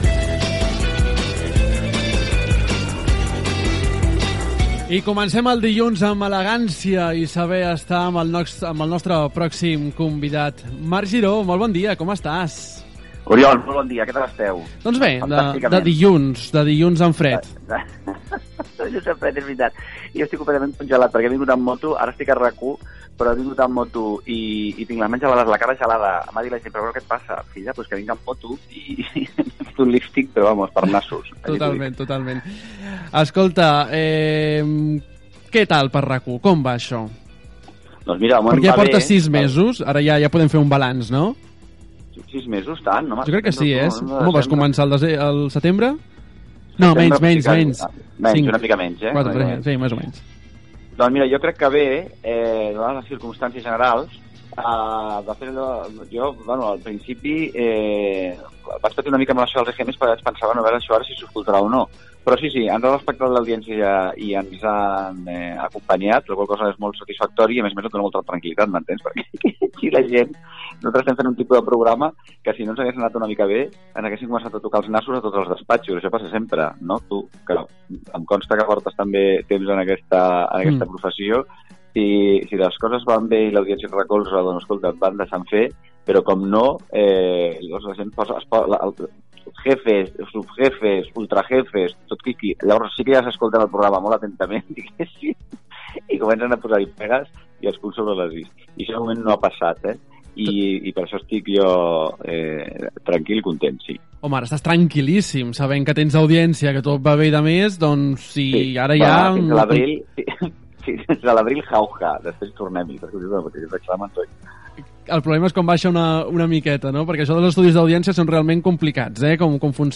Entrevista al presentador Marc Giró sis mesos després de començar el programa "Vostè primer", a RAC 1